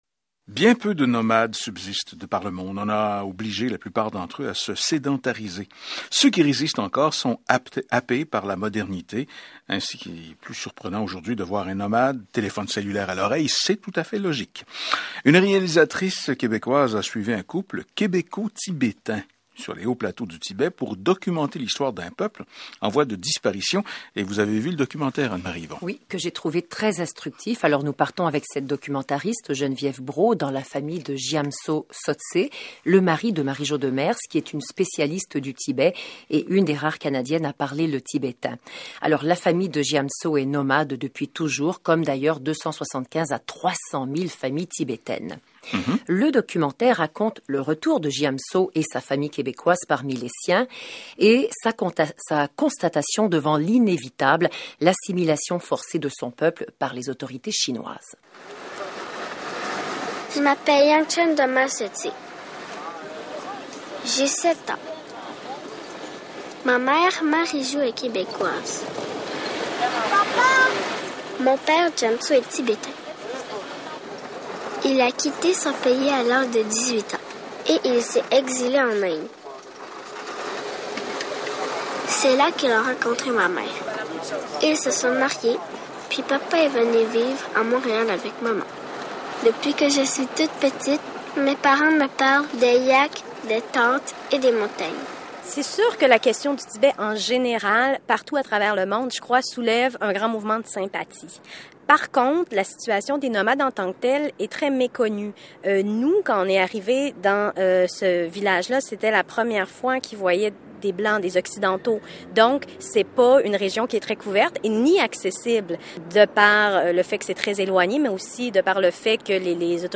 par | Classé dans : Communauté tibétaine, Reportages | 1